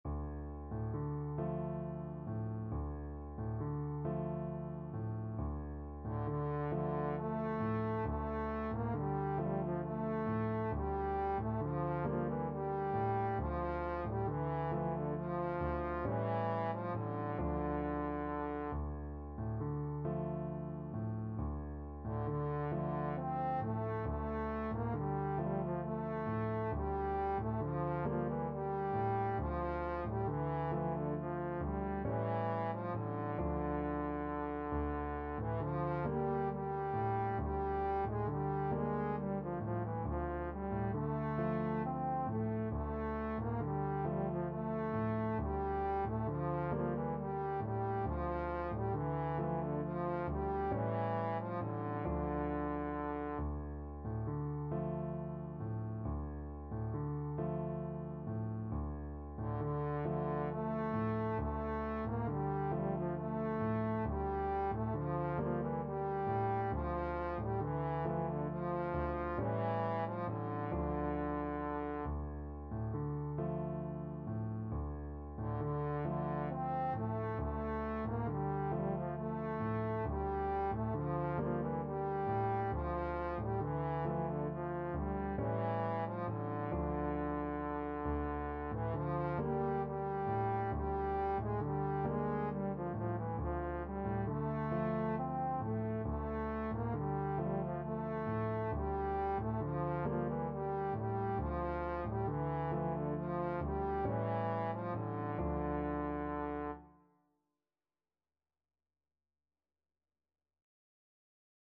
Trombone
Traditional Music of unknown author.
6/8 (View more 6/8 Music)
Gently rocking .=c.45
D minor (Sounding Pitch) (View more D minor Music for Trombone )
Turkish